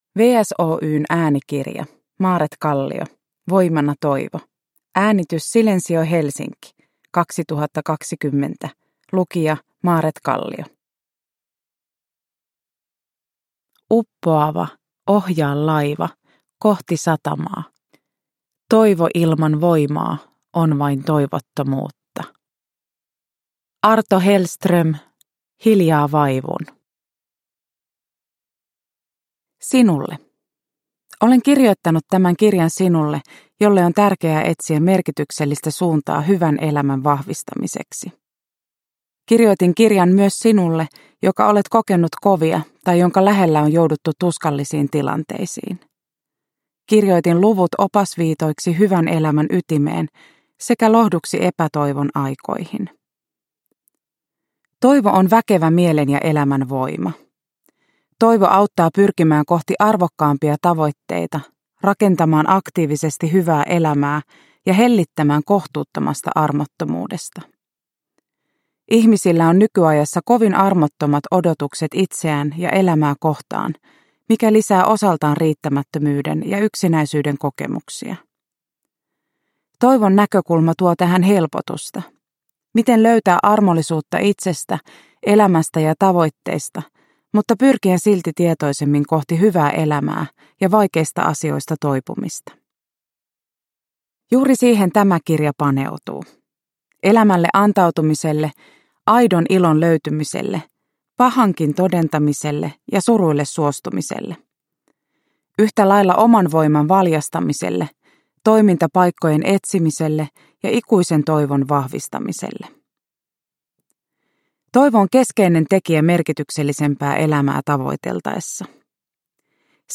Voimana toivo – Ljudbok
Uppläsare: Maaret Kallio